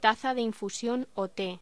Locución: Taza de infusión o té